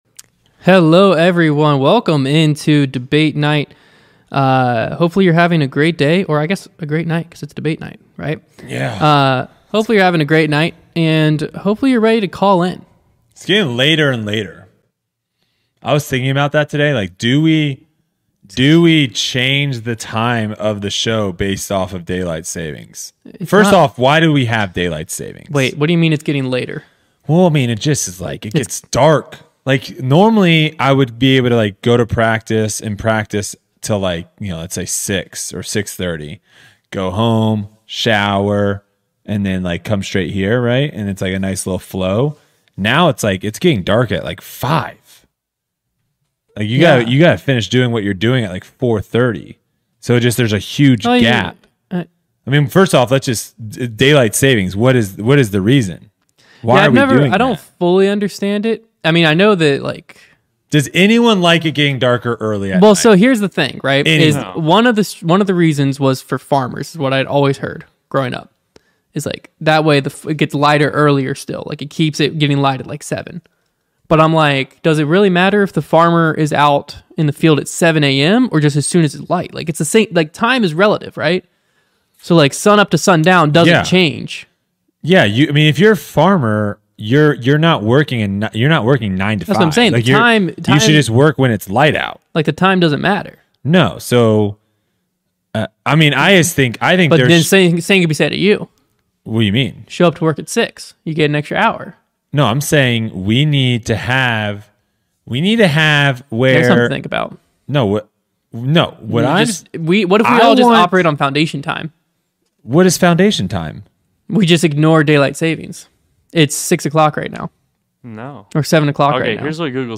Brodie Smith is back in the studio!